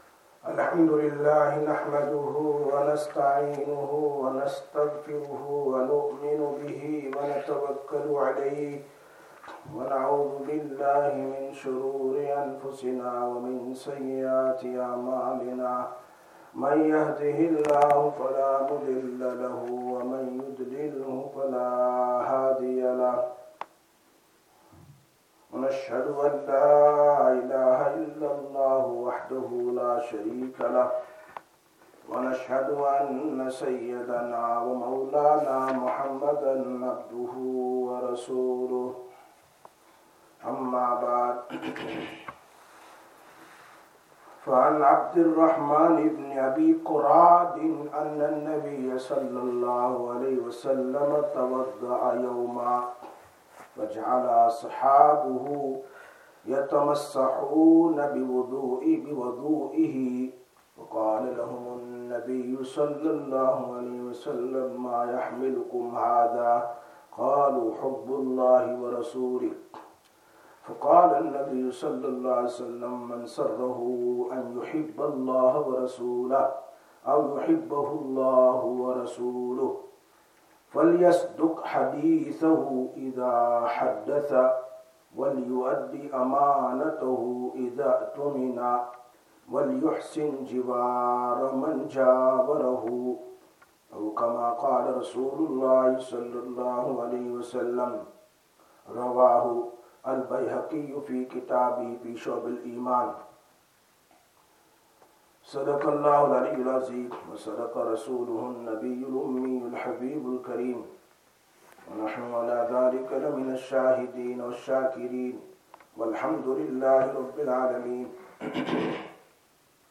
15/04/2026 Sisters Bayan, Masjid Quba